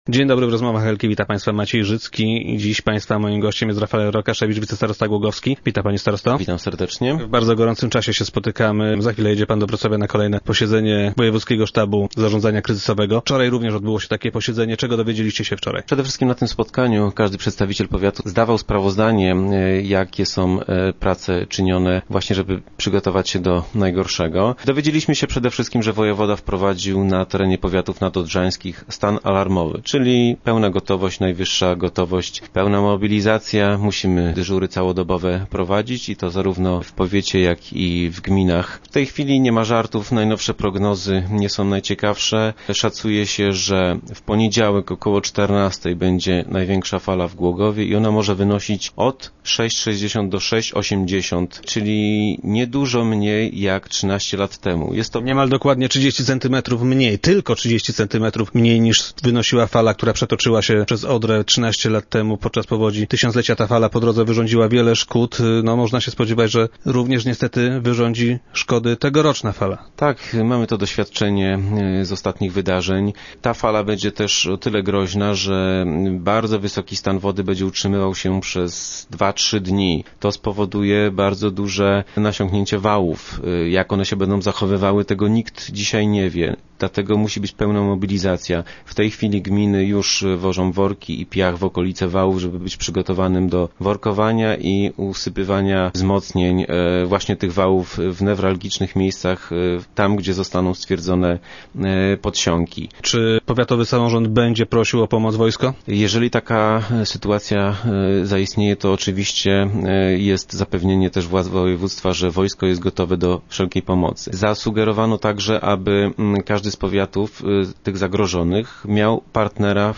Czy powiat głogowski jest przygotowany do walki z ewentualną powodzią? Gościem Rozmów Elki będzie wicestarosta Rafael Rokaszewicz, szef Powiatowego Zespołu Zarządzania Kryzysowego.